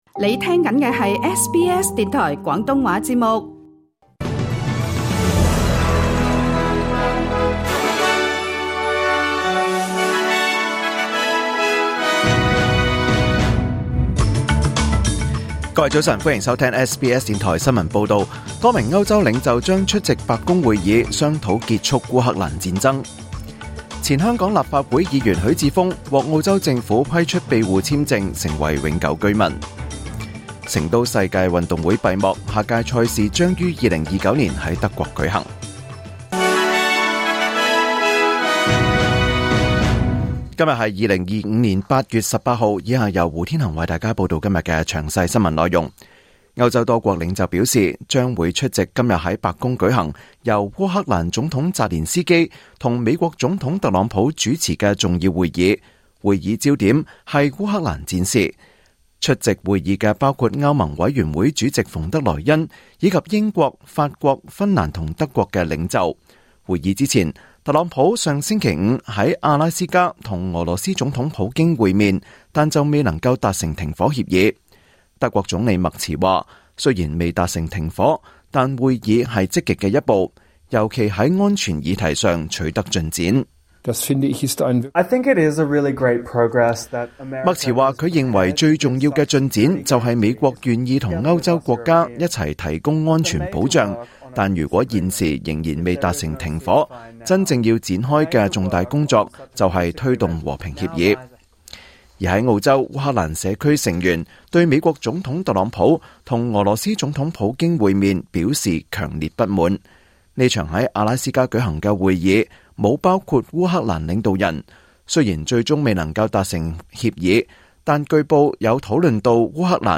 2025年8月18日SBS廣東話節目九點半新聞報道。